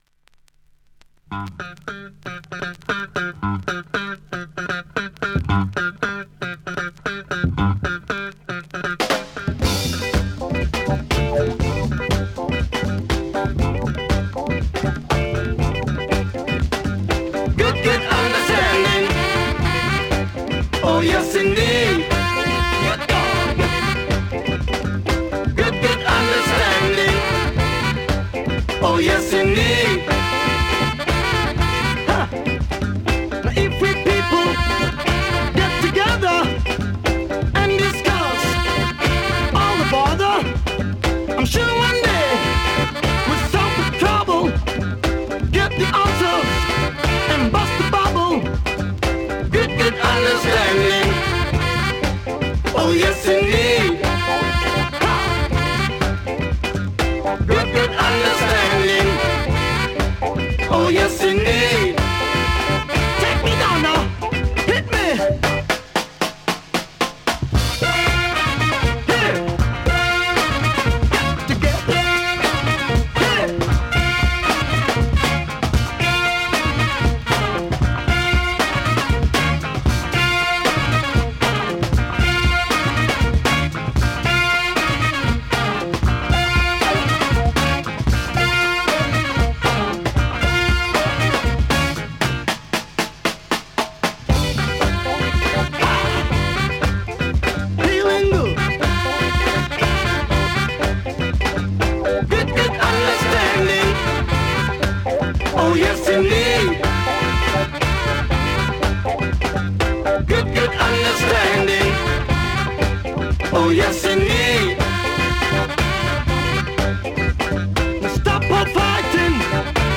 ◆UK盤 オリジナル 7"Single 45 RPM現物の試聴（両面すべて録音時間６分７秒）できます。